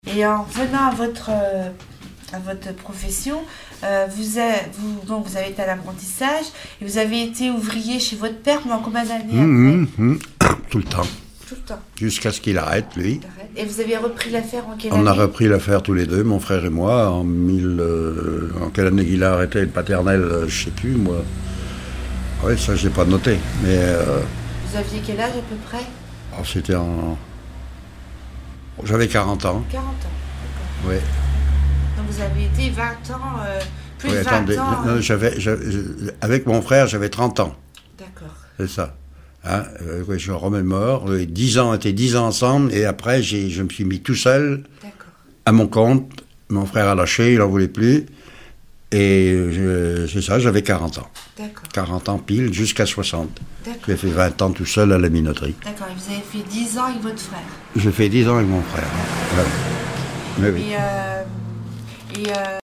Témoignage sur l'occupation allemande et la meunerie